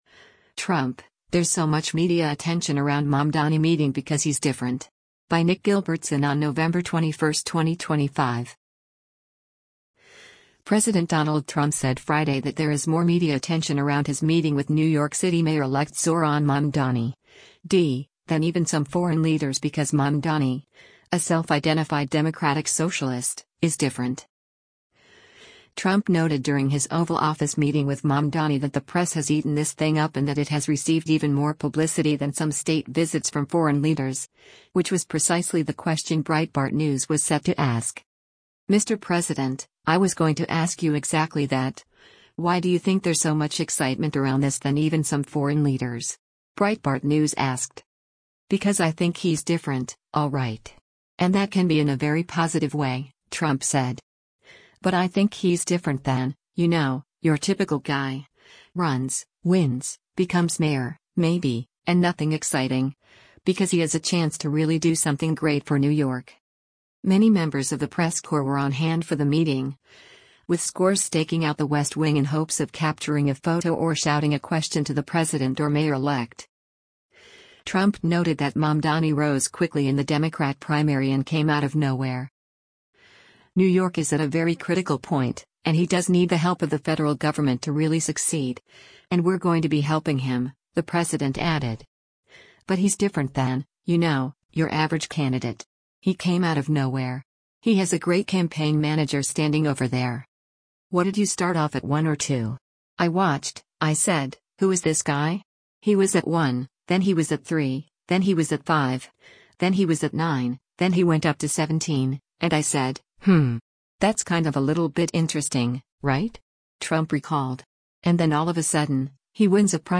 Trump noted during his Oval Office meeting with Mamdani that “the press has eaten this thing up” and that it has received even more publicity than some state visits from foreign leaders, which was precisely the question Breitbart News was set to ask.
Many members of the press corps were on hand for the meeting, with scores staking out the West Wing in hopes of capturing a photo or shouting a question to the president or mayor-elect.